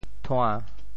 潮州拼音“tuan1”的详细信息
调: 低 潮州府城POJ thuaⁿ
thua~1.mp3